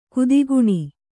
♪ kudiguṇi